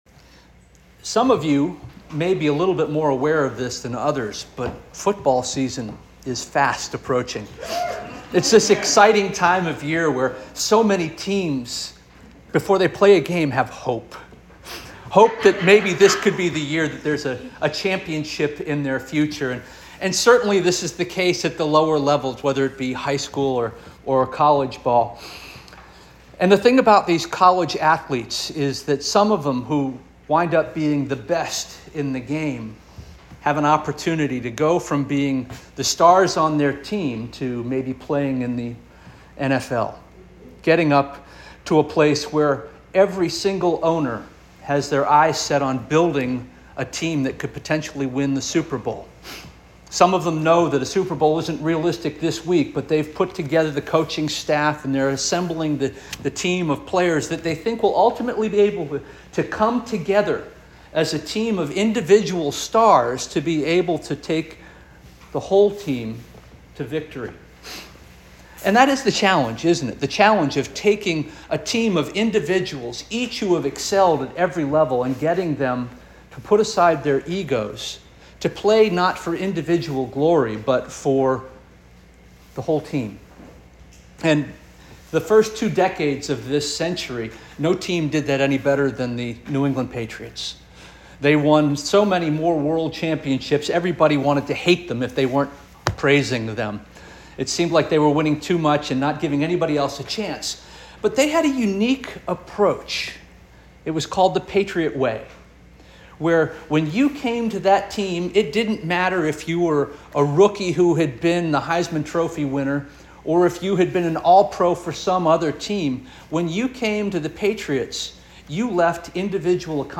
August 18 2024 Sermon